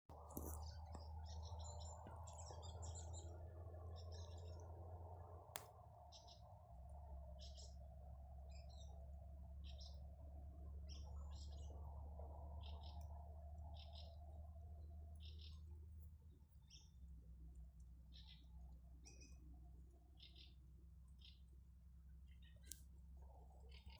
Birds -> Thrushes ->
Fieldfare, Turdus pilaris
StatusSinging male in breeding season
NotesViens dzirdams lapu kokā, otrs strazds - blakus lapu koku mežiņā. Lauku mājas ar lieliem veciem lapu kokiem, blakus lauks.